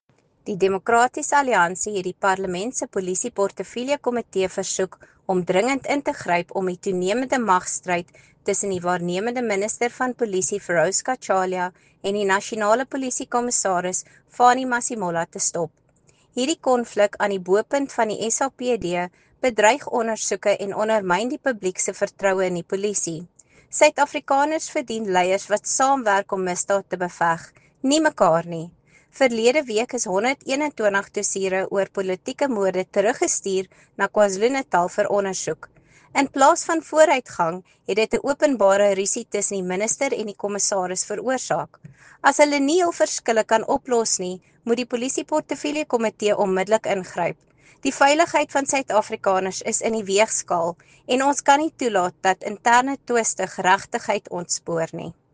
Afrikaans soundbites by Lisa Schickerling MP.